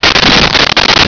Sfx Poof4
sfx_poof4.wav